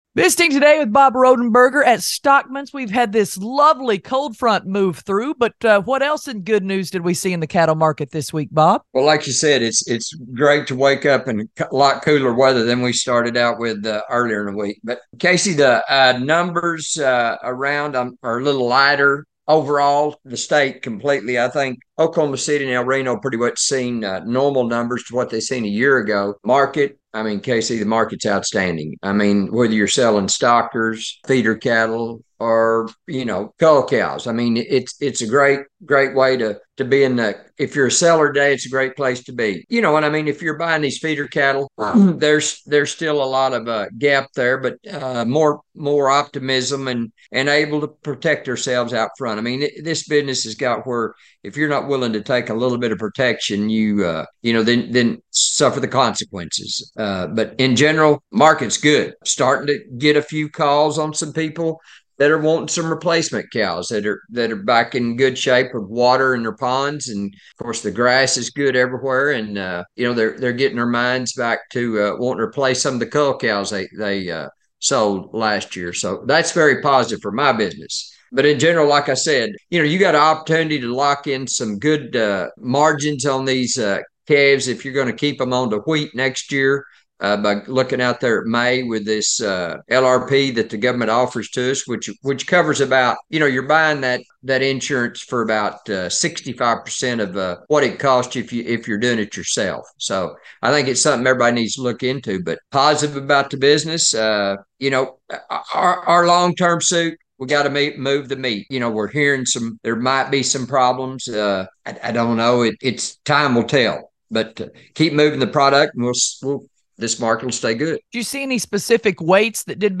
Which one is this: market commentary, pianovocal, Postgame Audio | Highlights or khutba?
market commentary